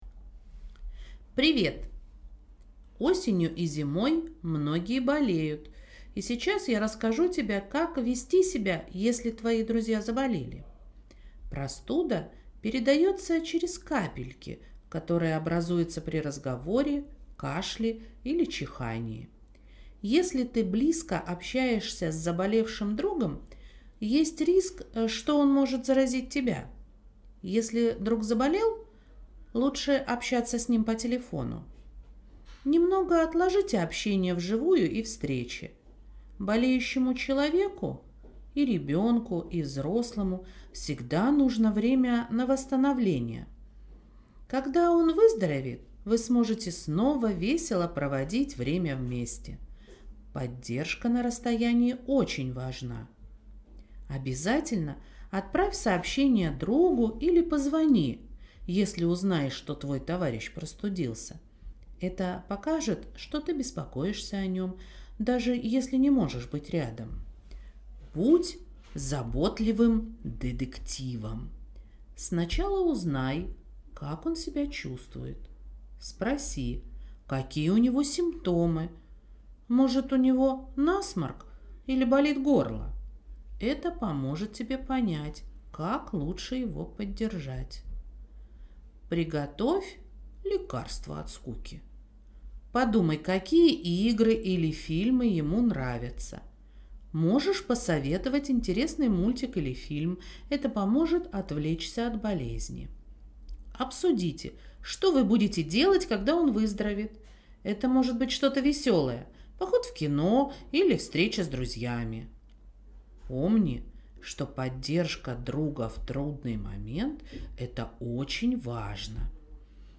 Аудиосказки